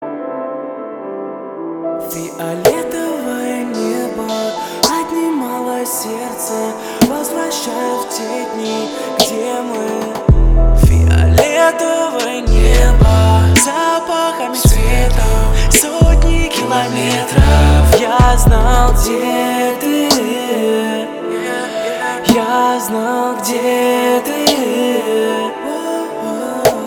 мужской вокал
русский рэп
спокойные
романтичные
Bass